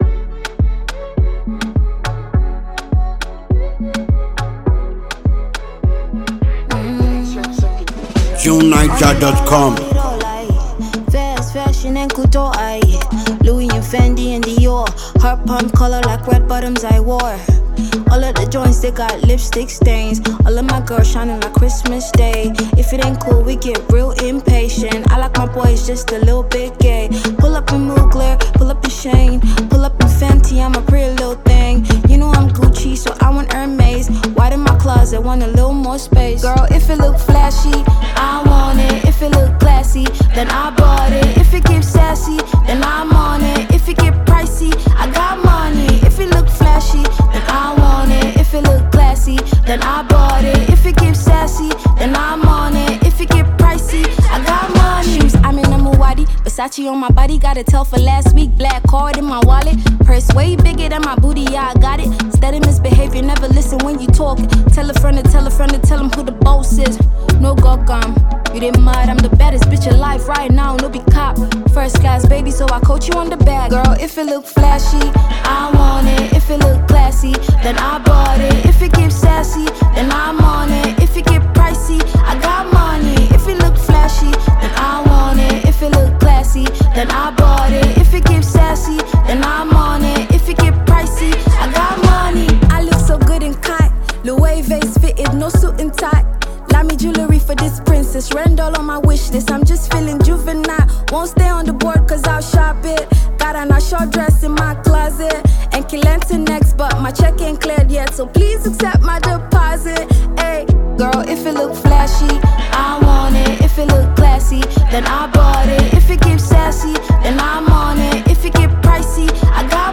a fantastic Nigerian female singer-songwriter.